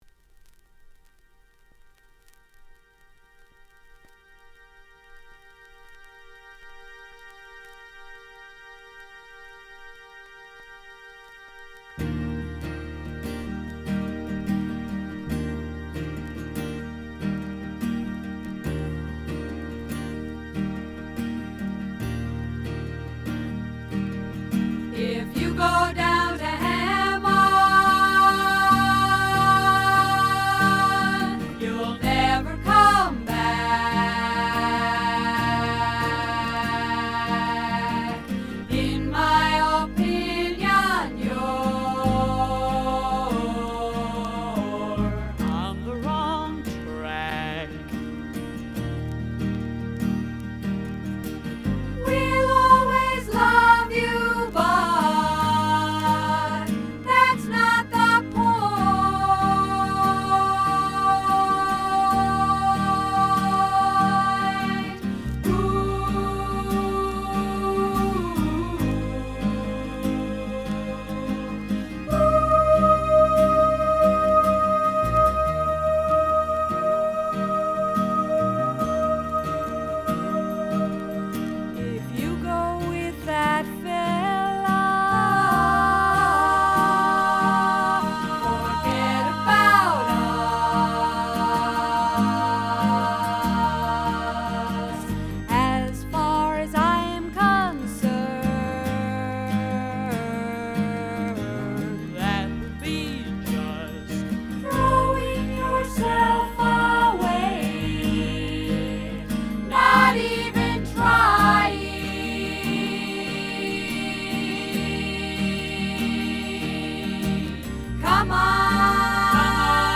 Genre: Indie Folk.